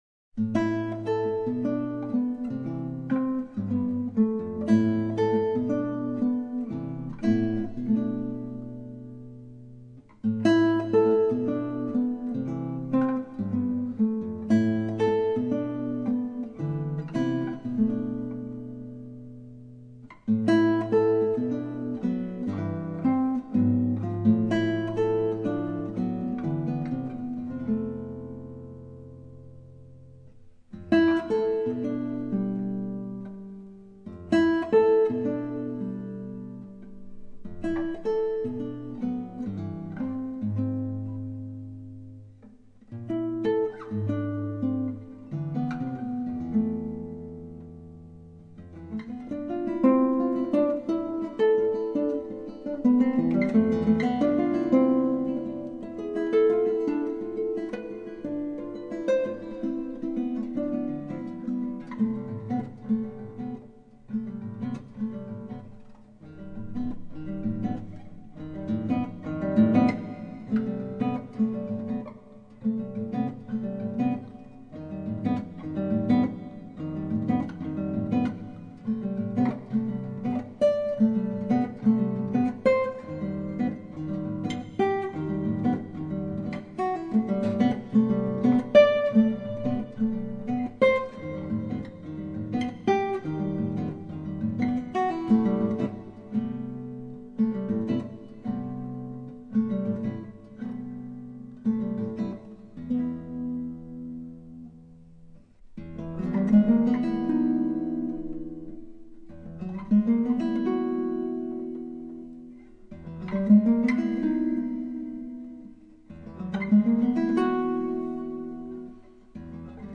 6 cordes
Enregistré à la Salle "Le Royal" de Pessac (Gironde)